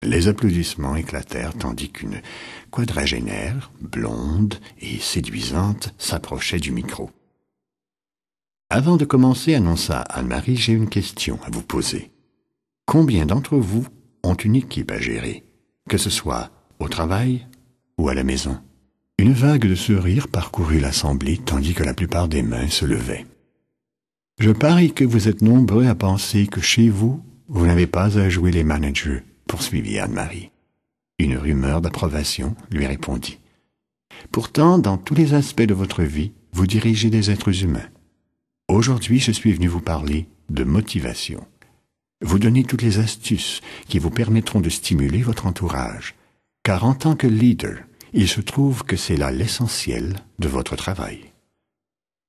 Extrait gratuit - Soyez fiers de vous de Ken Blanchard